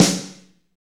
Index of /90_sSampleCDs/Northstar - Drumscapes Roland/DRM_Slow Shuffle/KIT_S_S Kit 2 x